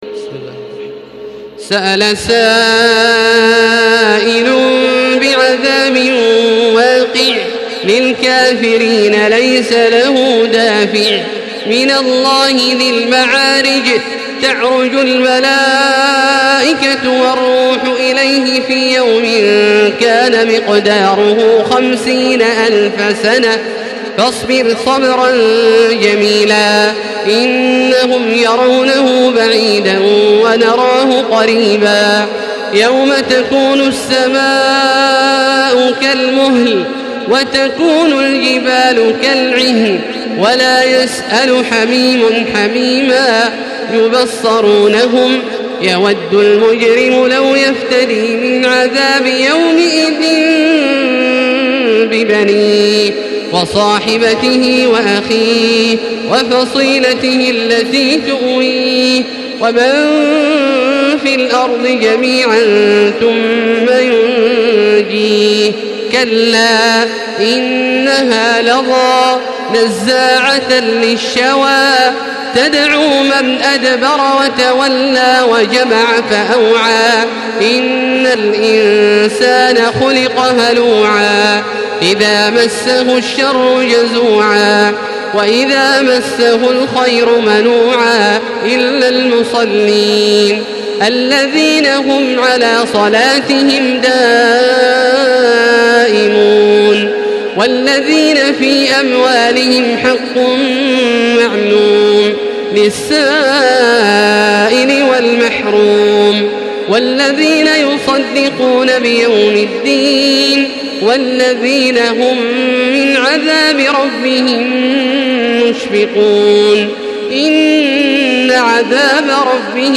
Surah Al-Maarij MP3 in the Voice of Makkah Taraweeh 1435 in Hafs Narration
Surah Al-Maarij MP3 by Makkah Taraweeh 1435 in Hafs An Asim narration.
Murattal